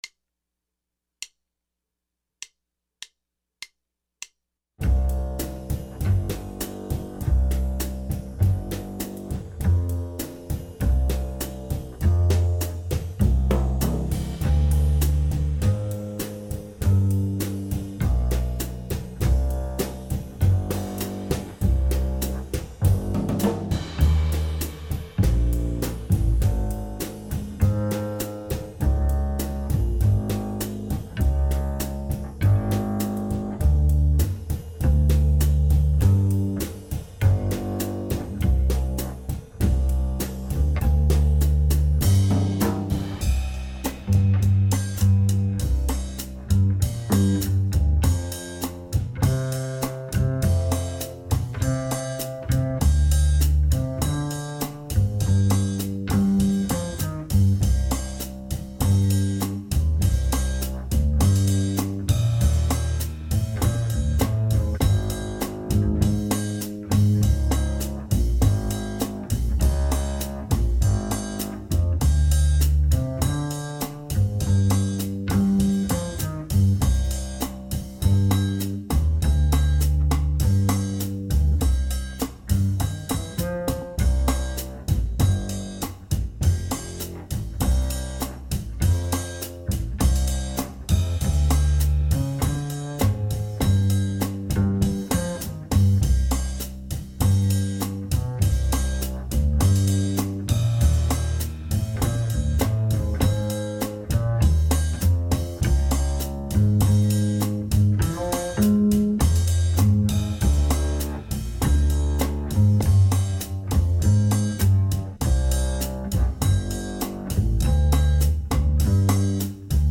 Play authentic Brazilian chords & rhythms as well as solo over this jazz jam session classic.